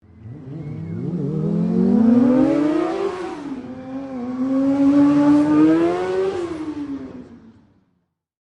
Motorcycle Racing
Motorcycle Racing is a free sfx sound effect available for download in MP3 format.
yt_WTlieTyJync_motorcycle_racing.mp3